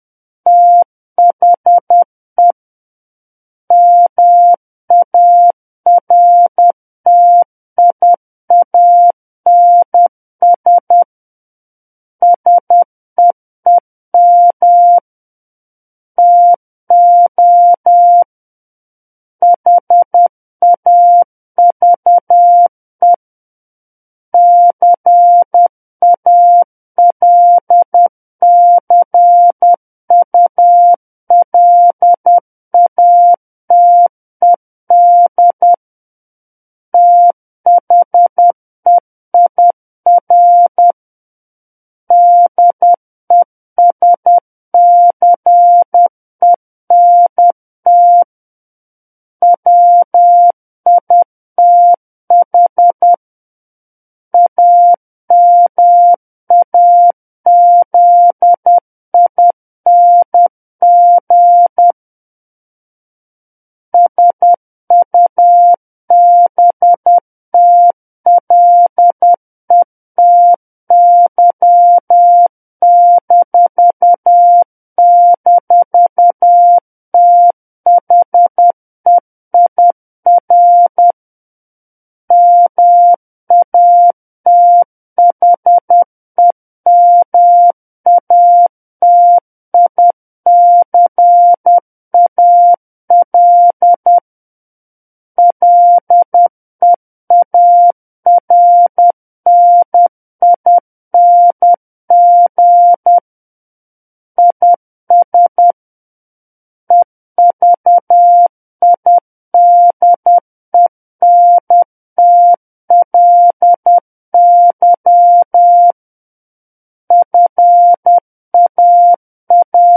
SKCC Learning Center - Morse Code Practice Files
War of the Worlds - 01-Chapter 1 - 10 WPM